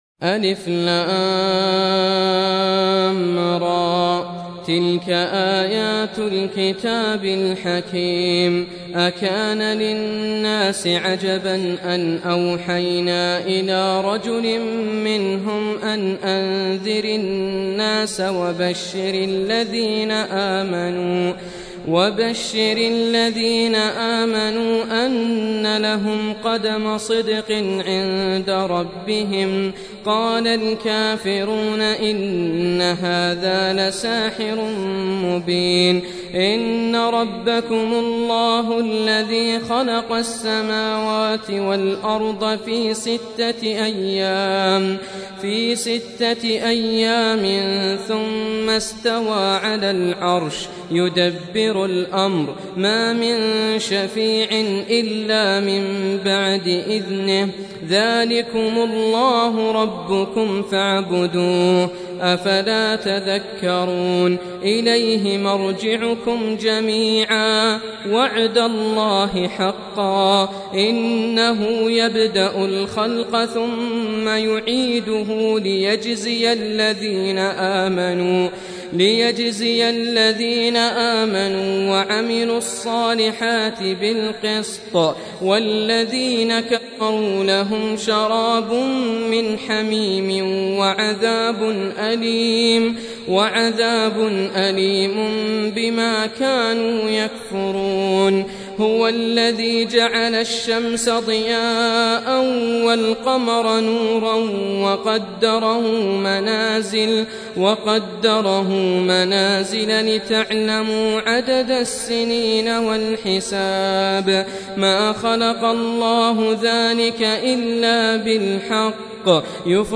Surah Repeating تكرار السورة Download Surah حمّل السورة Reciting Murattalah Audio for 10.